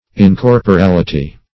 Search Result for " incorporality" : The Collaborative International Dictionary of English v.0.48: Incorporality \In*cor`po*ral"i*ty\, n. [L. incorporalitas: cf. F. incorporalit['e].]
incorporality.mp3